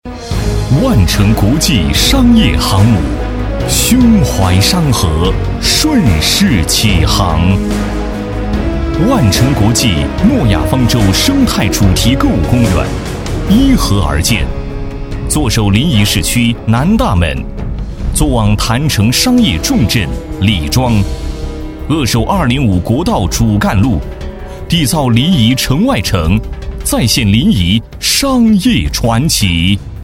广告配音
地产男7号
激情力度 建筑房产